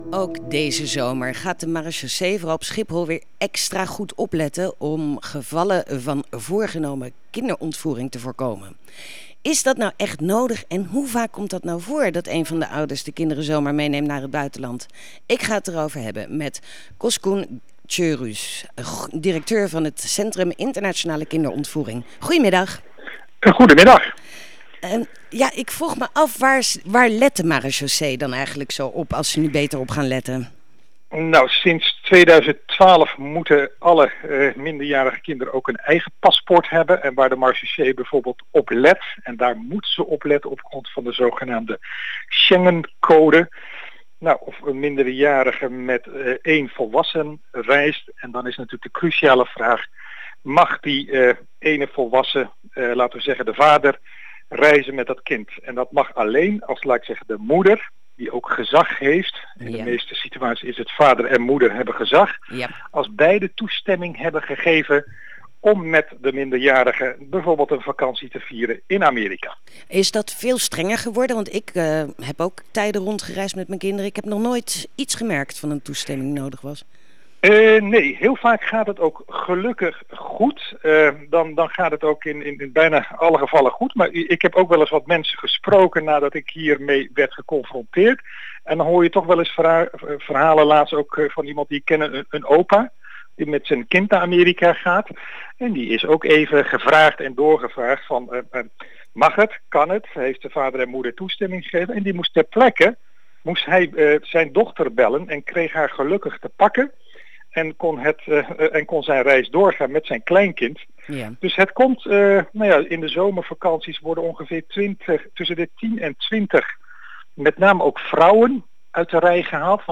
Ook deze zomer gaat de Marechaussee vooral op Schiphol weer extra goed opletten op gevallen van (voorgenomen) kinderontvoering. In Ritme van de stad vertelt Coskun Çörüz, directeur van het Centrum Internationale Kinderontvoering, waar de marechaussee op let.